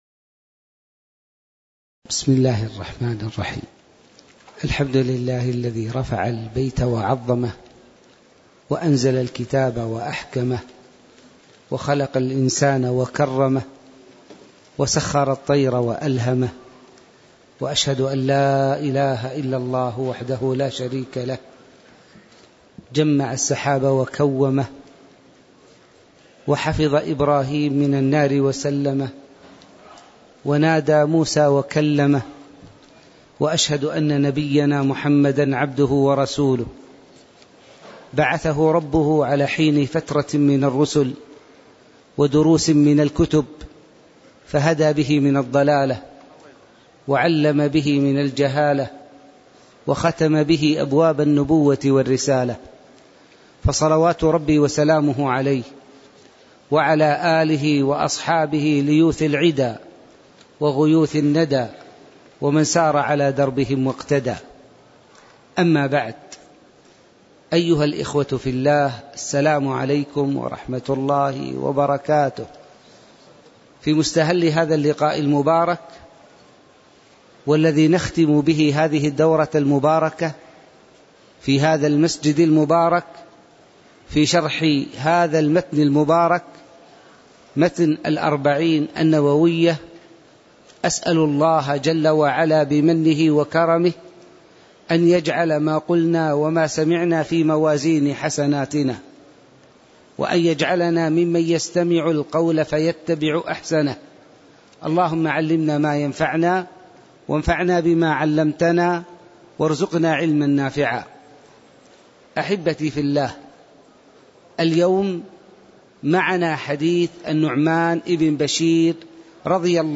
تاريخ النشر ٨ جمادى الآخرة ١٤٣٧ هـ المكان: المسجد النبوي الشيخ